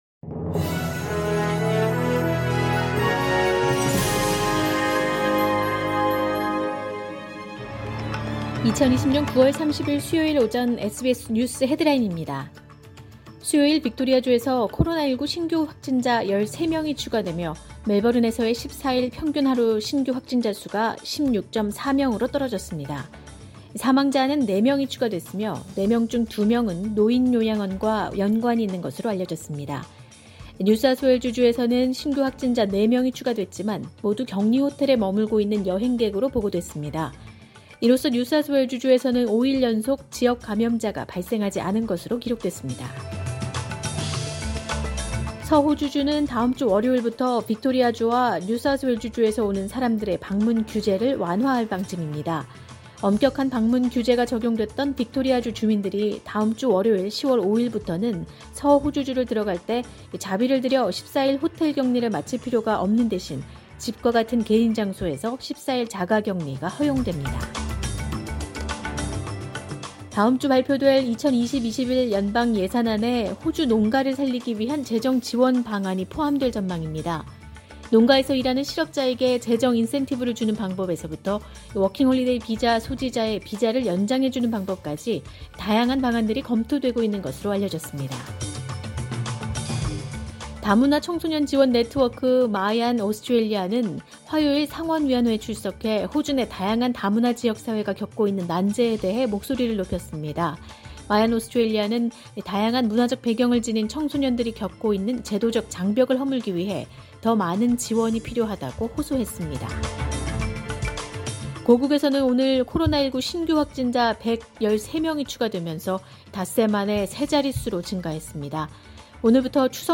2020년 9월 30일 수요일 오전의 SBS 뉴스 헤드라인입니다.